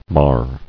[mar]